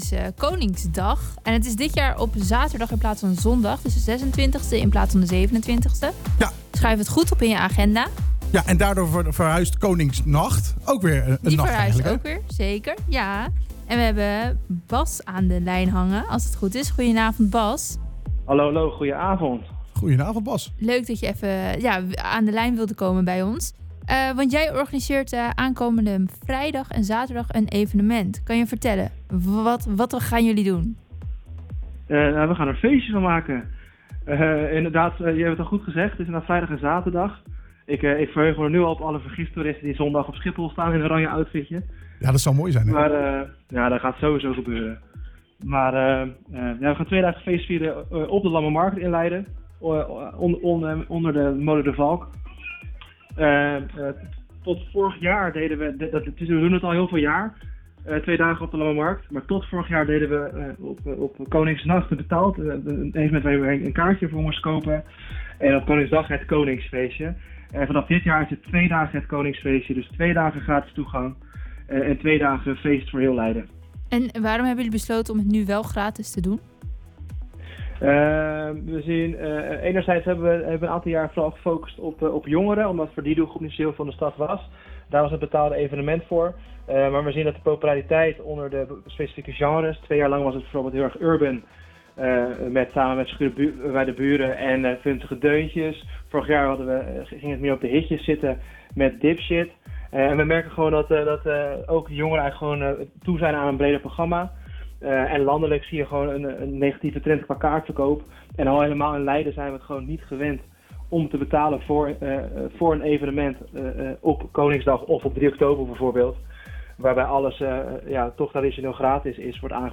hing aan de lijn